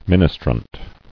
[min·is·trant]